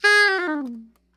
SOPRANO FALL
SOP SHRT G 4.wav